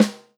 S_snare_2.wav